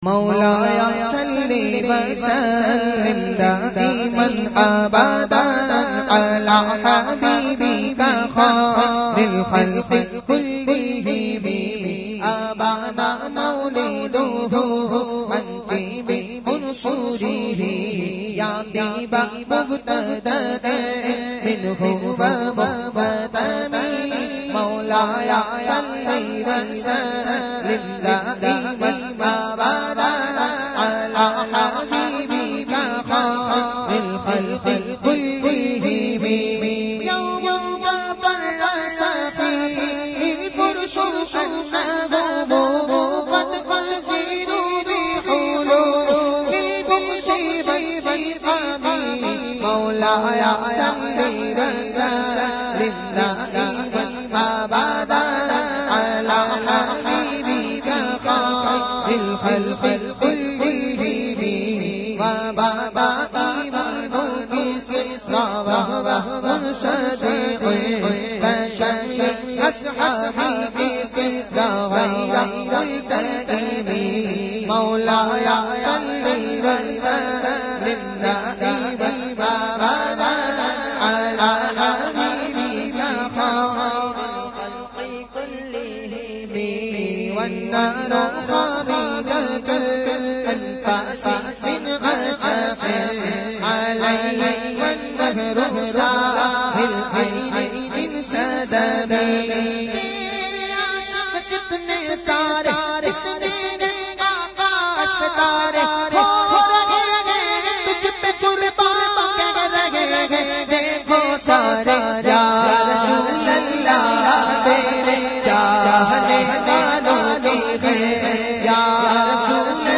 recited by famous Naat Khawan